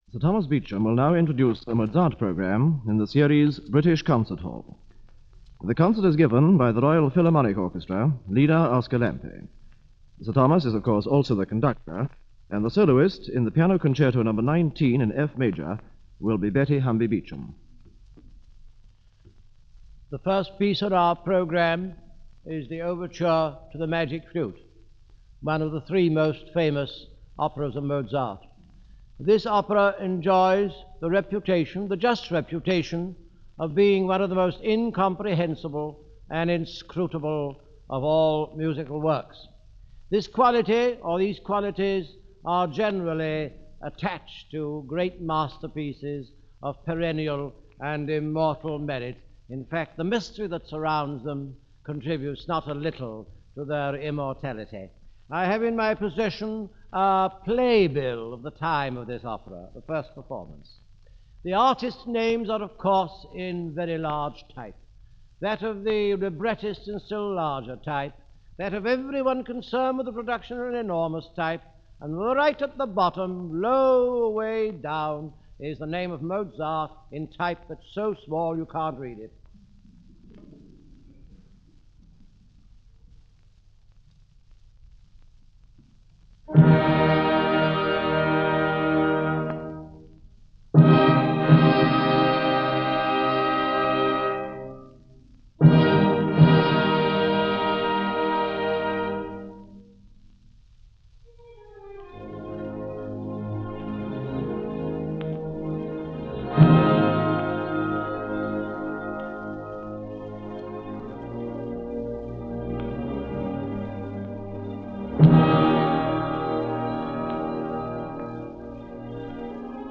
Starting off with the Overture to The Magic Flute and then to the Divertimento Number 2 and the Piano concerto Number 19 featuring Betty Humby Beecham, piano and ending with the Overture to The Marriage of Figaro.